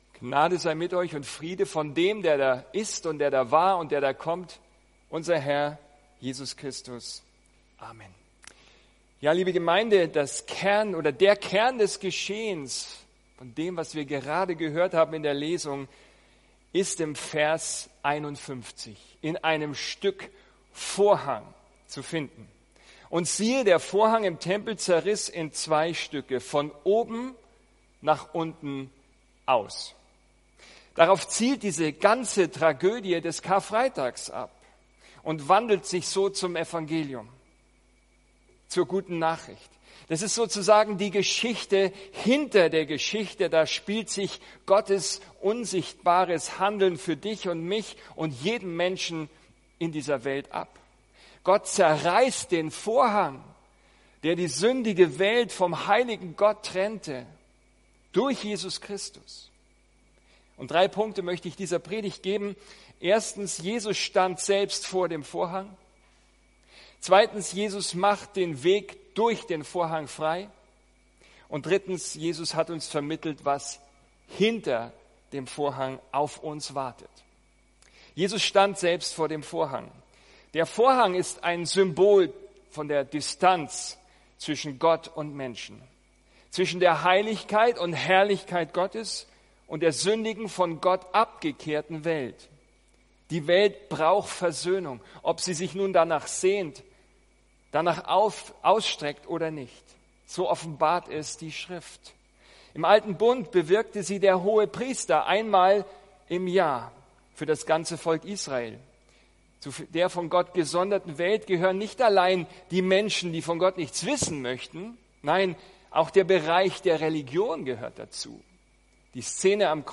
Ein Studienblatt zur Predigt ist im Ordner “Notes” verfügbar